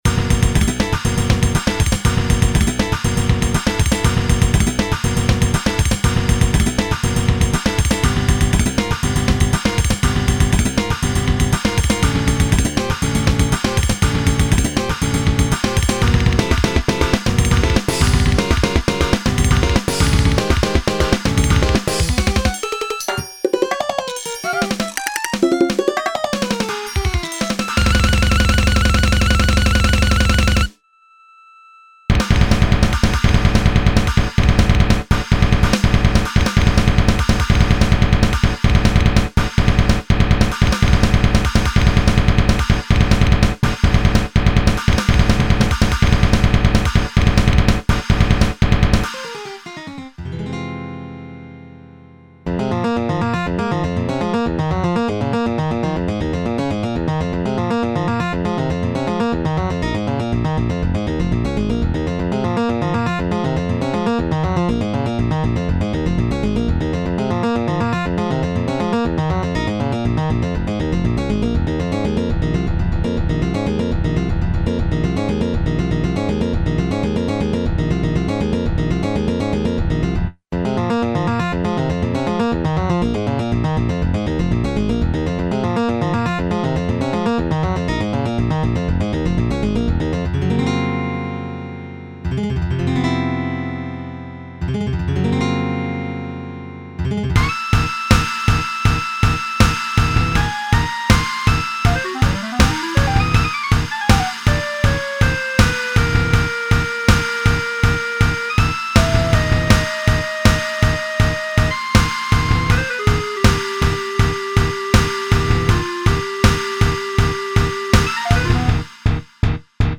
Ohmsägør, c'est une base metal ajoutant beaucoup d'éléments étrangers, du poprock au bebop en passant par le classique baroque, le death, le prog, la salsa.
La musique reste accessible mais particulièrement casse tête et technique, avec moults changements de tempo et harmonisations dangereusement...délicates, je dirais.
EDIT: J'ai rajouté une conversion midi en mp3, je sais que tout le monde n'a pas un midi génial donc ca donnera une idée un peu plus claire j'espère.